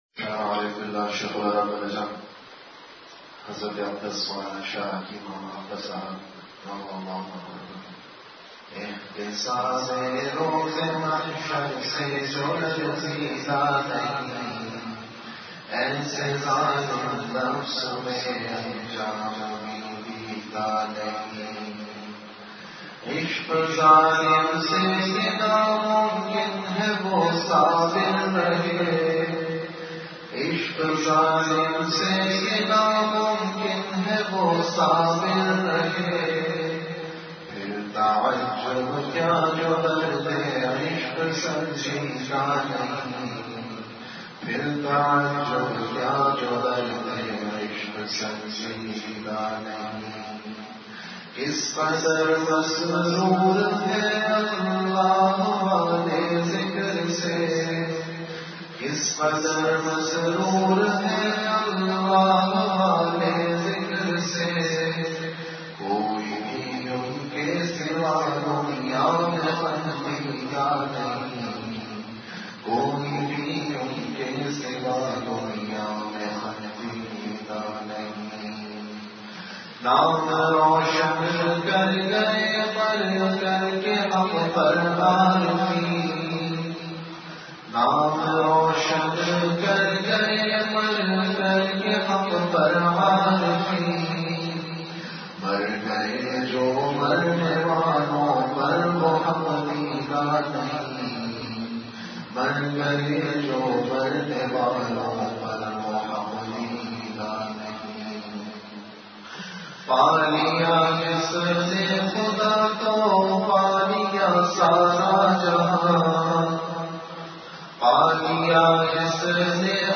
An Islamic audio bayan